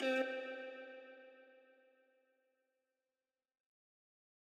The Town Guitar One Shot.wav